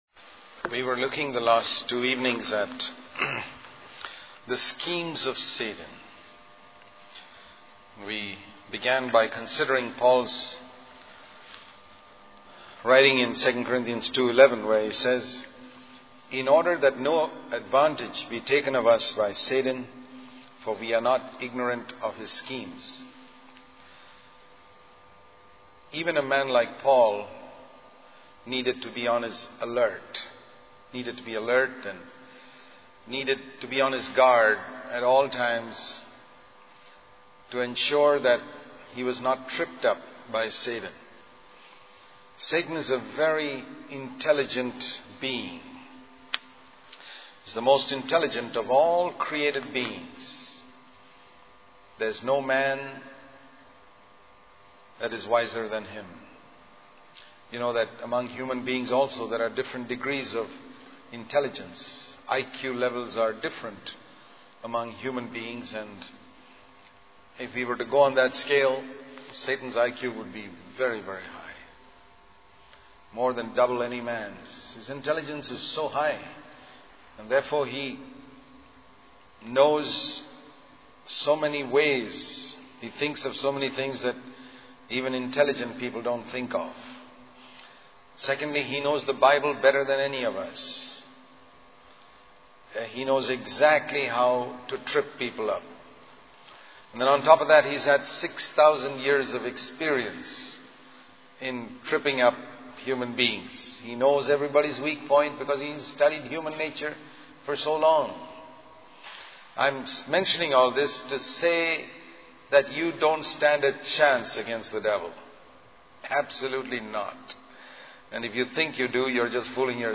In this sermon, the preacher emphasizes the importance of prioritizing the spiritual over the physical in various aspects of our lives. He warns against being influenced by advertisements and worldly desires that promote material possessions as essential.